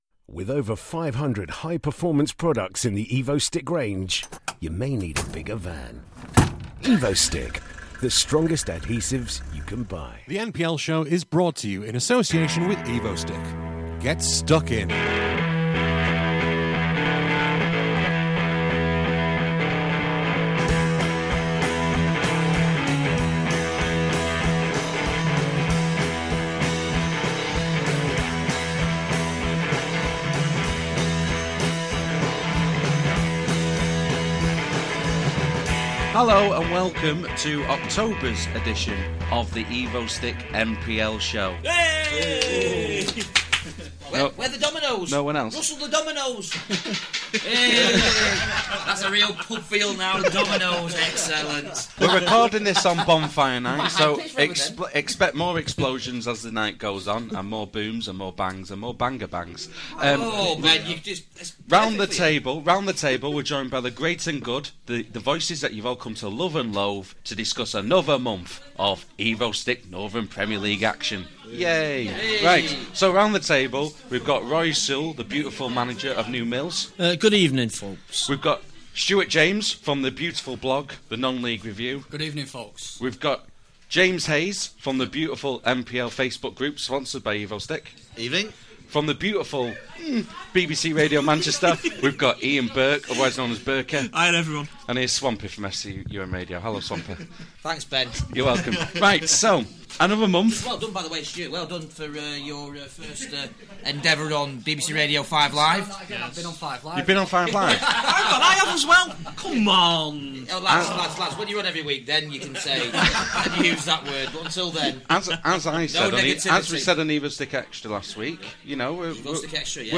With Thanks: This programme was recorded at the Magnet in Stockport on Wednesday 5th November 2014.